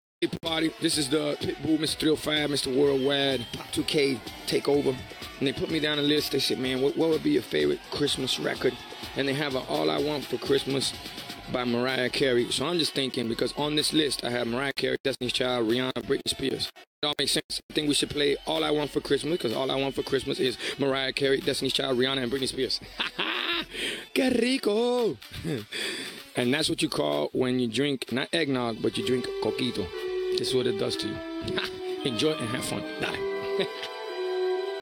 Pitbull took over SiriusXM’s Pop 2K for a holiday party take-over today.